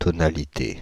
Ääntäminen
Paris: IPA: [tɔ.na.li.te]